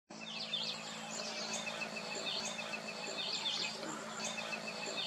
Gallito de Collar (Melanopareia maximiliani)
Nombre en inglés: Olive-crowned Crescentchest
Fase de la vida: Adulto
Localidad o área protegida: Miramar de Ansenuza
Condición: Silvestre
Certeza: Vocalización Grabada